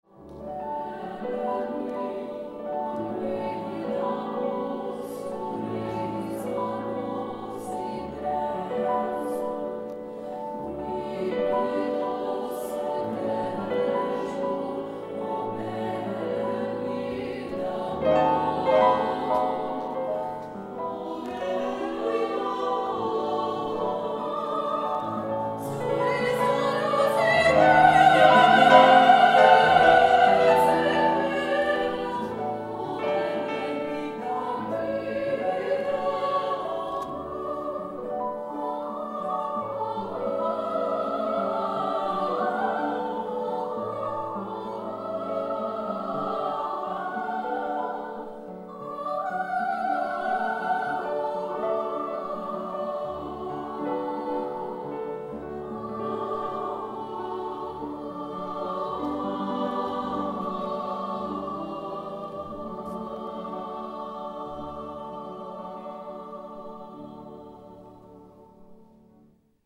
Audios | Cor de Cambra Femení Scherzo
cor_scherzo_belle_nuit_j._offenbach_0.mp3